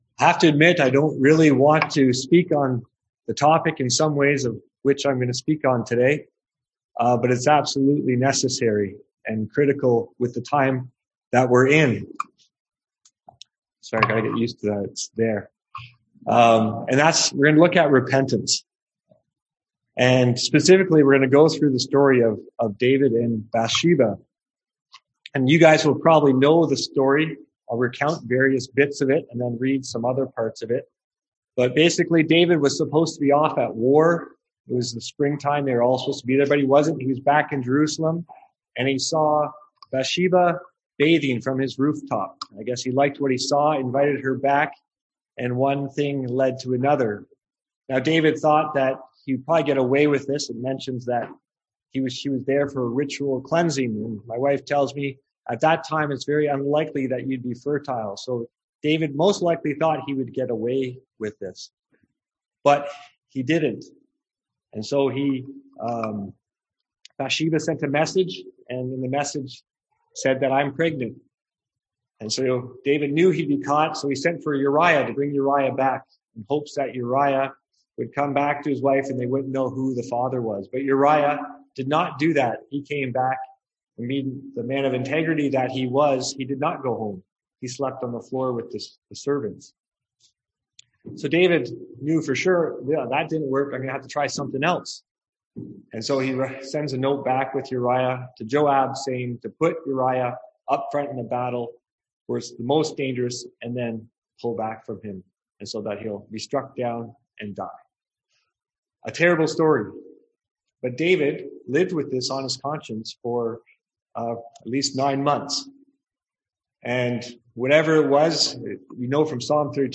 Passage: 2 Samuel 11-12, Psalm 32 Service Type: Sunday AM Topics: David , Repentance , Sin